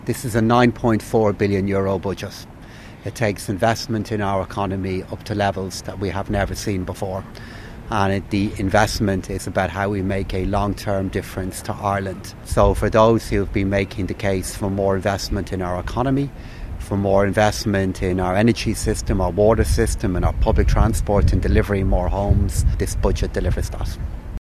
Speaking in the past few minutes, Paschal Donohoe says the Budget is about investing in the country’s future……………